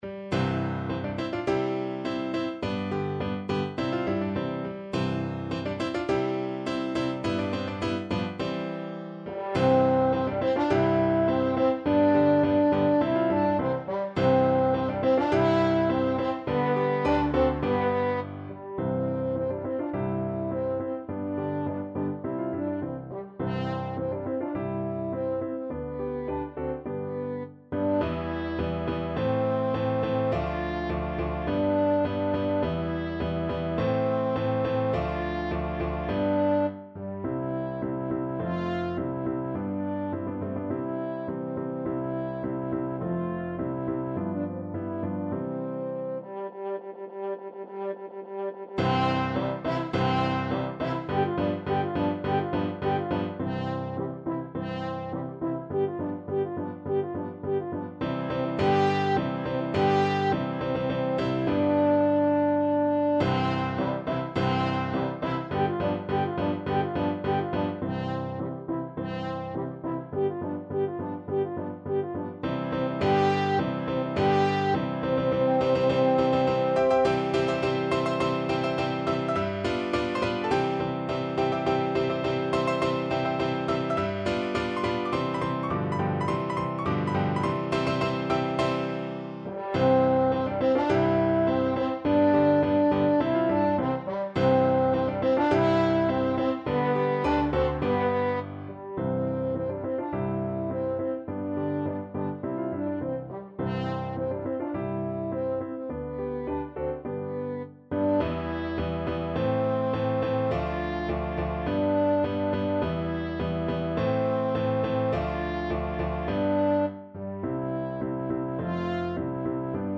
French Horn
C major (Sounding Pitch) G major (French Horn in F) (View more C major Music for French Horn )
~ = 100 Molto vivace =104
2/4 (View more 2/4 Music)
Classical (View more Classical French Horn Music)